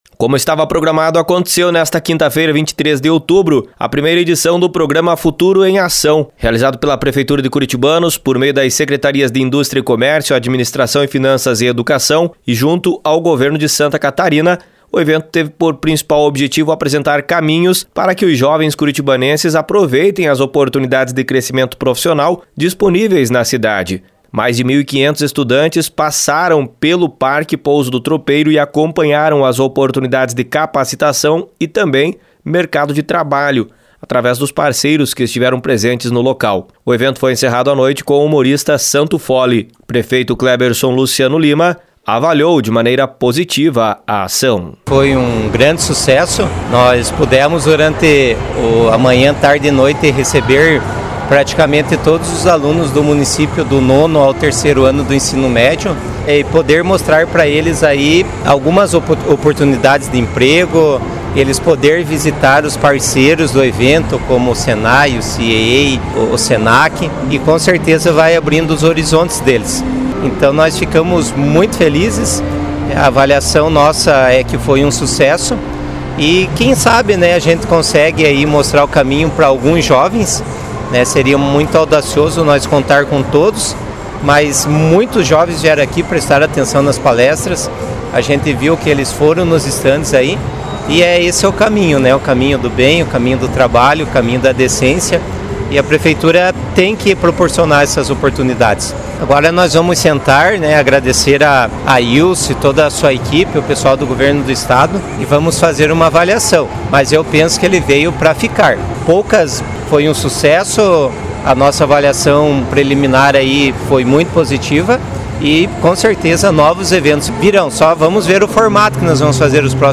Informações com repórter